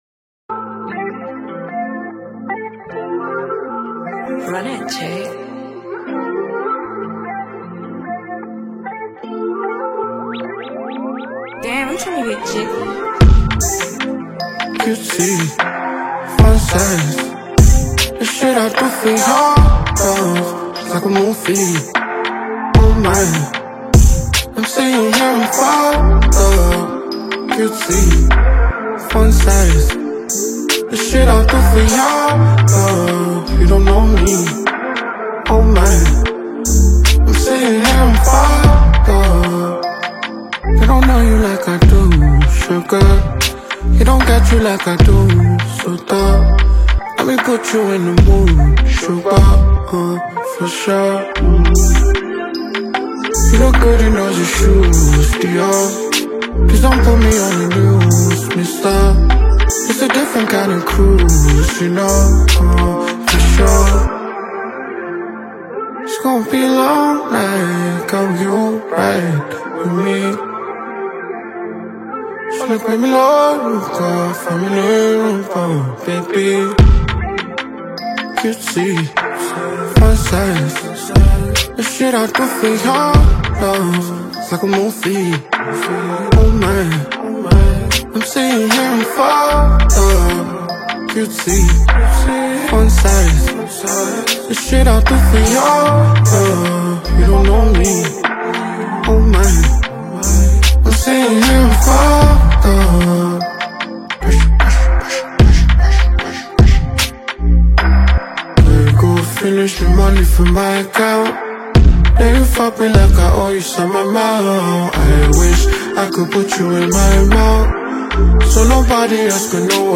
Nigerian extremely good singer-songwriter